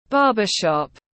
Barbershop /ˈbɑː.bə.ʃɒp/